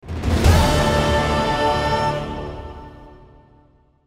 • Качество: 320, Stereo
громкие
женский голос